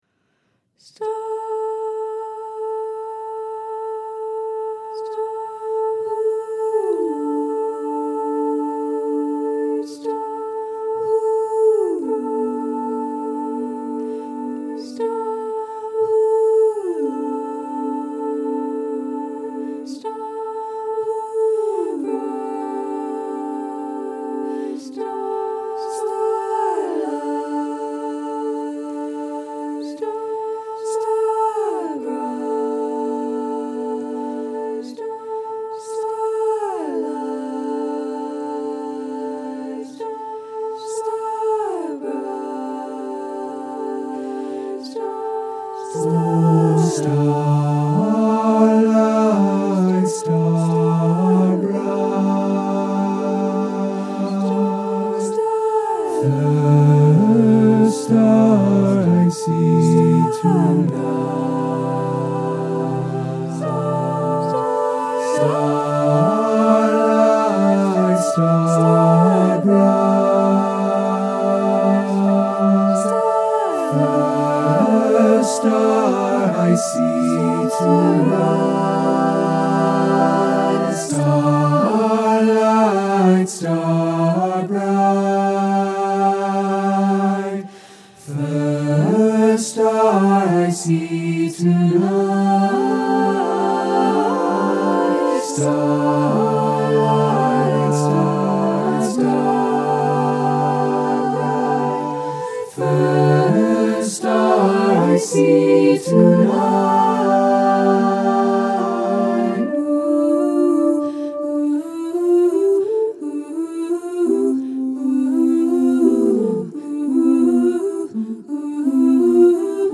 (Demo performance)
for SATB div. a cappella choir